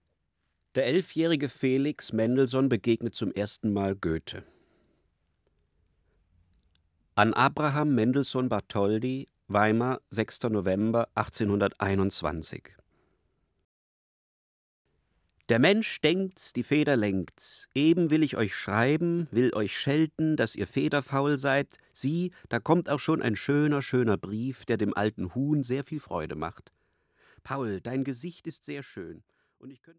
Durs Grünbein - Sprecher